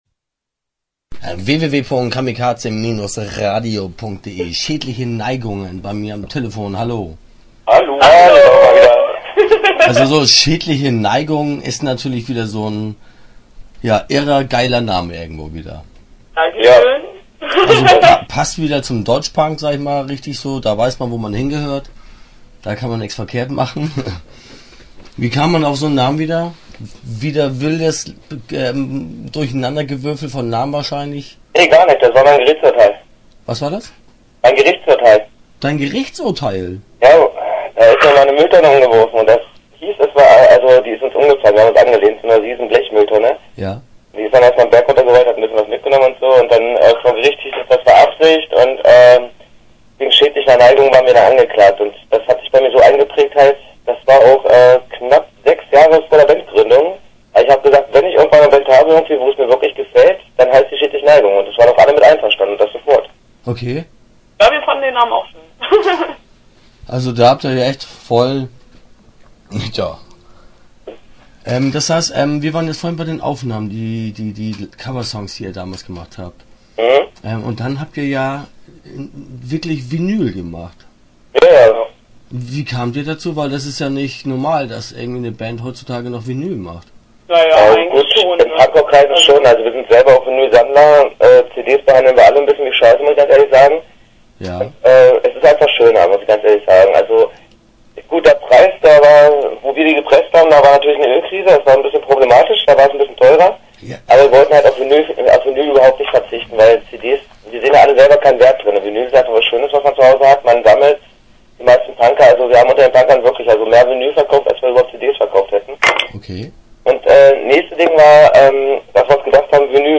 Start » Interviews » Schädliche Neigungen